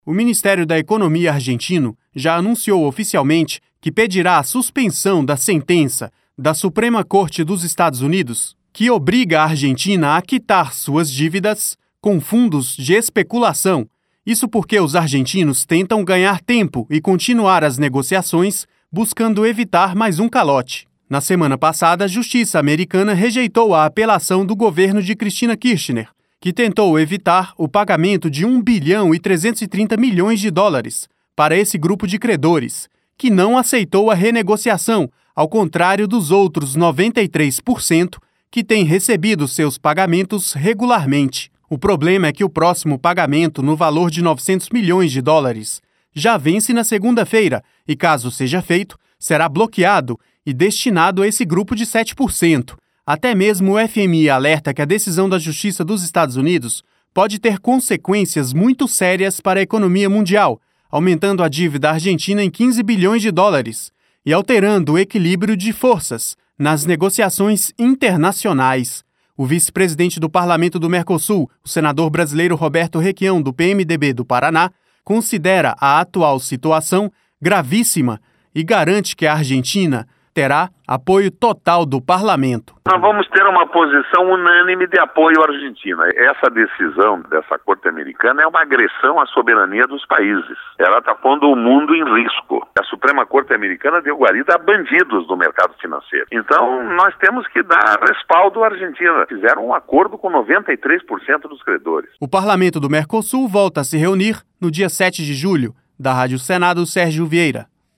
LOC: O PARLASUL VAI DAR APOIO UNÂNIME AO GOVERNO DA ARGENTINA, NA SUA TENTATIVA DE RENEGOCIAR O PAGAMENTO COM OS CREDORES INTERNACIONAIS. LOC: A GARANTIA É DO VICE-PRESIDENTE DO PARLAMENTO, O SENADOR BRASILEIRO ROBERTO REQUIÃO.